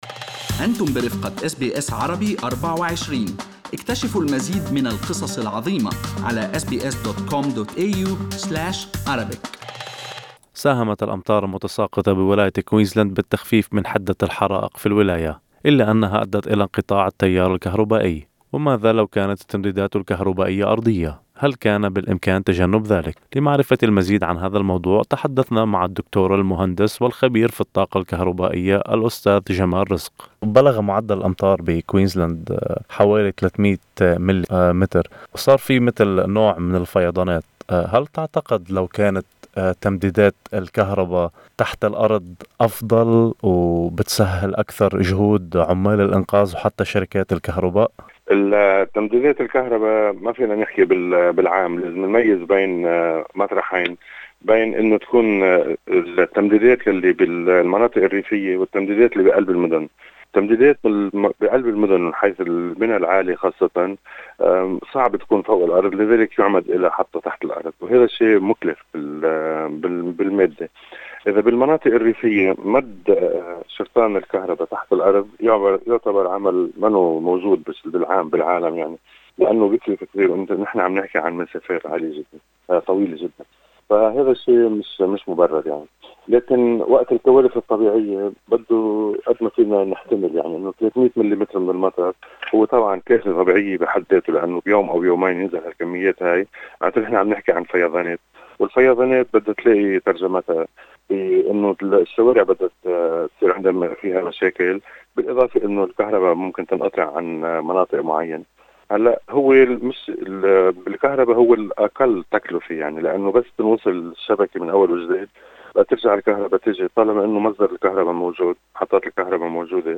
ولكن ماذا لو كانت التمديدات الكهربائية تحت الأرض، هل كانت ستسهل مهام قوات الطورائ وشركات الكهرباء لإيصال التيار الكهربائي؟ لمعرفة المزيد تحدثنا مع الدكتور المهندس الكهربائي الخبير في الطاقة المتجددة